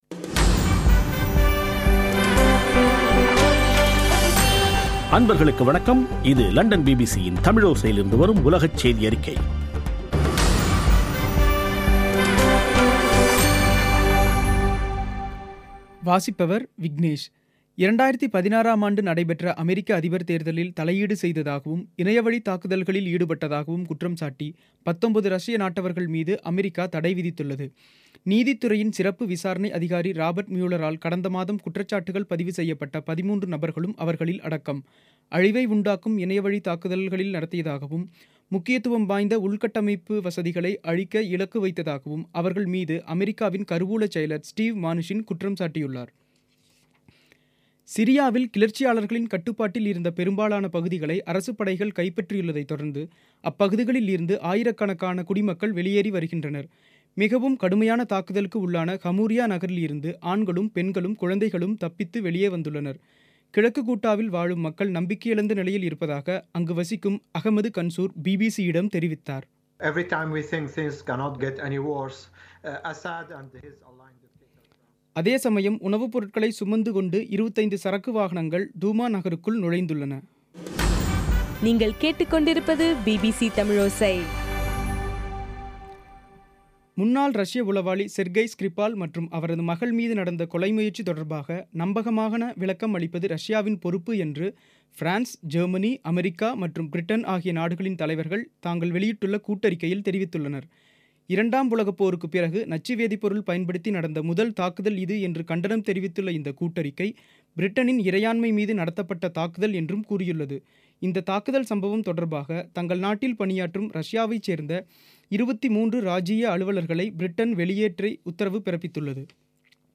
பிபிசி தமிழோசை செய்தியறிக்கை (15/03/2018)